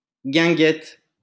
The guinguette (French pronunciation: [ɡɛ̃ɡɛt]